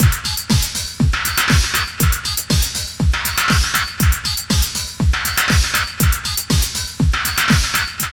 15.5 LOOP3.wav